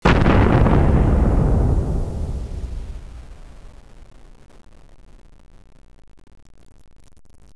nuke.wav